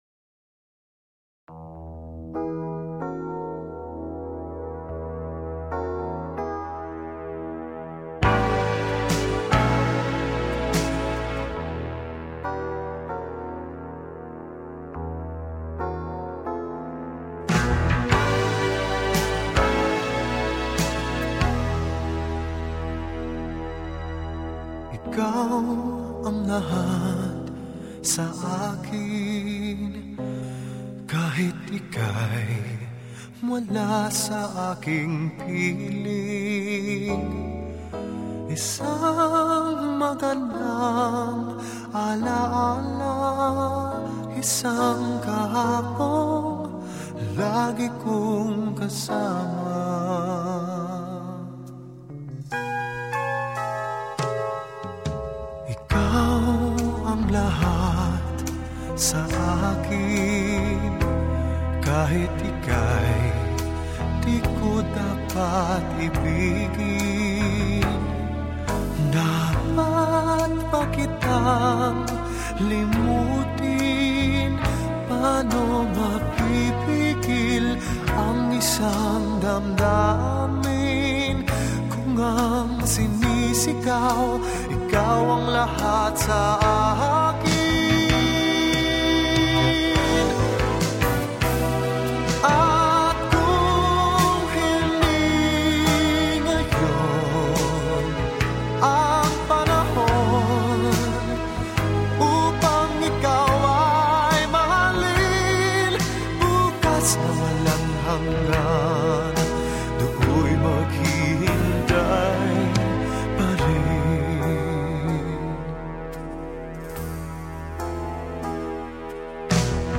Tags: Sentimental Classic All time favorite Sweet Passionate